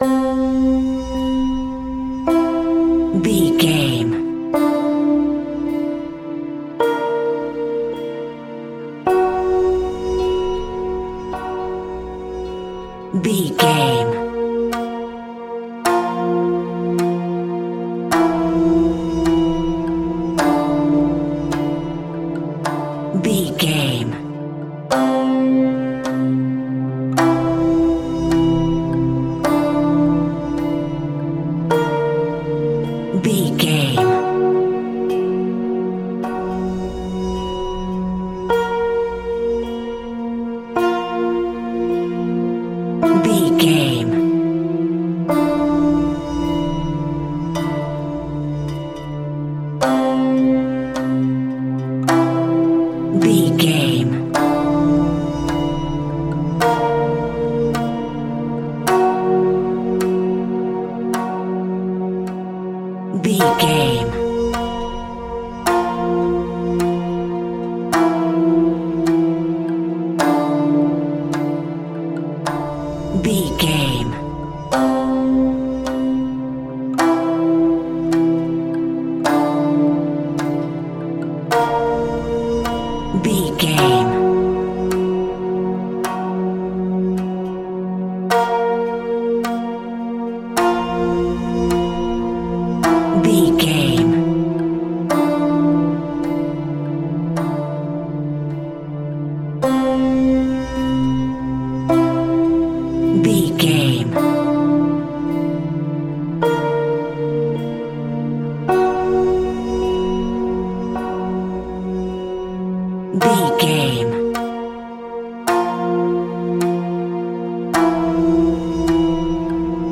Atonal
Slow